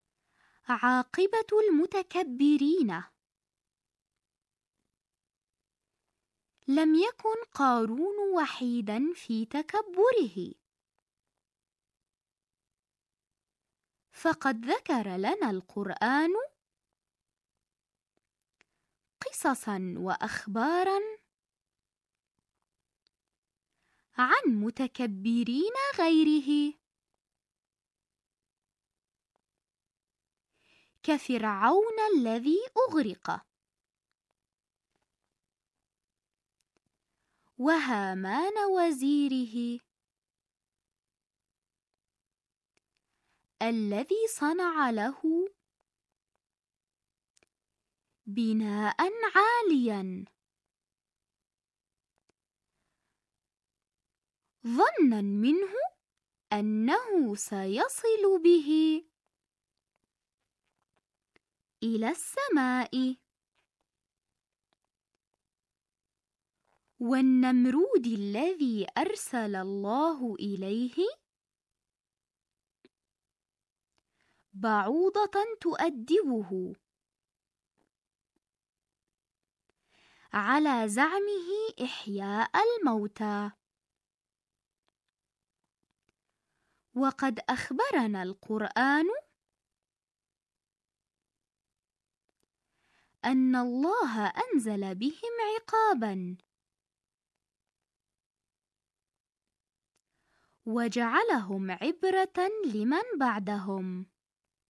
نص املاء عاقبه المتكبرين mp3 تمارين عربي صف خامس فصل اول منهاج اردني 2024/2025